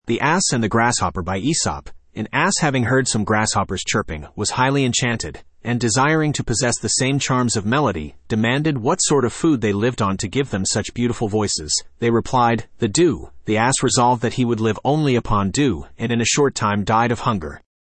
Studio (Male)
the-ass-and-the-grasshopper-en-US-Studio-M-77ee3f1e.mp3